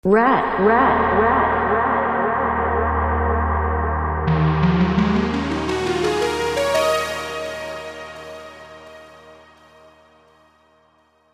Sound Buttons: Sound Buttons View : Rat Piano